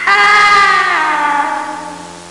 Falling Sound Effect
Download a high-quality falling sound effect.
falling.mp3